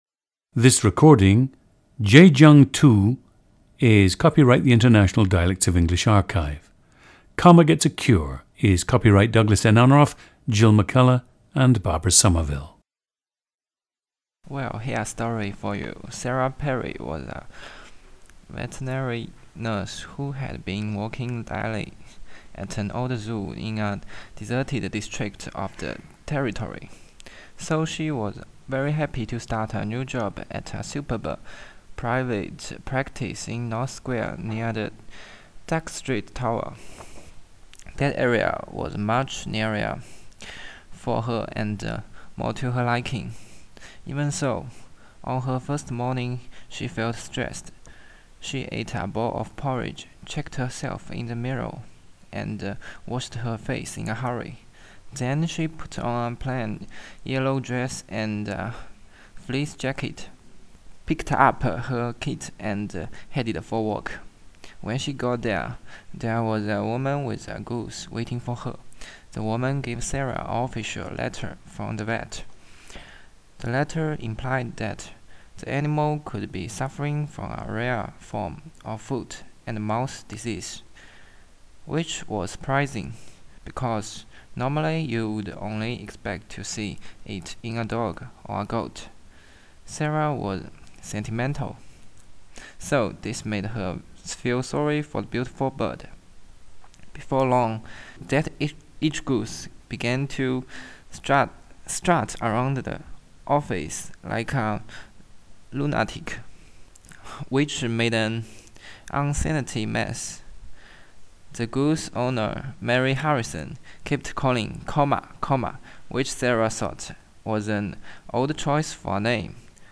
GENDER: male
However, despite being an English major, his overall exposure to native English speakers has been slim, and his accent is strong.
Given the linguistic diversity in Zhejiang, most young people today begin to learn Putonghua at an early age, and this will also have had some influence on his speech pattern and pronunciation.
Short readings from the analects of Confucius
The subject now goes on to read the following abstracts from the Analects of Confucius in his own Huzhou dialect.
This is a nice, strong accent with few of the usual Chinese problems with minimal pairs, although the problem with multiple /s/ sounds in possessives and plurals is evident, in “goose’s,” for example.